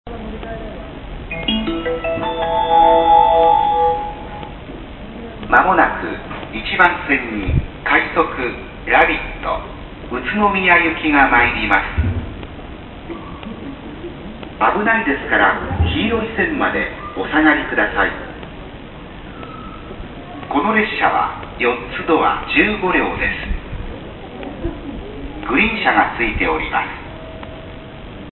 接近放送「快速ラビット」宇都宮行き「快速ラビット」宇都宮行き4ドア15両の接近放送です。